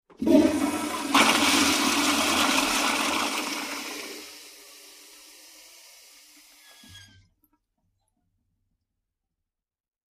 Toilet is flushed. Flush, Toilet Commode, Flush Urinal, Flush